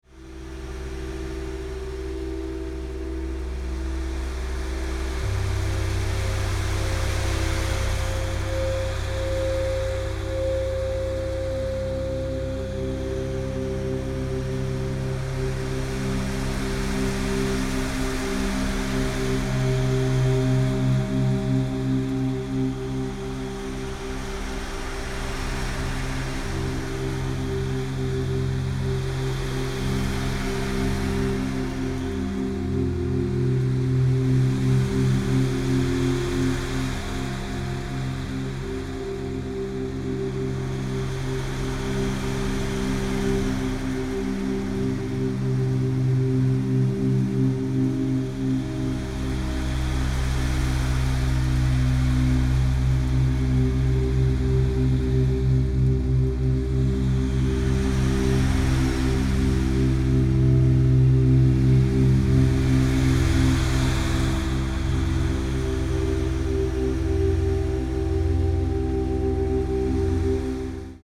multidimensional ambient meditation music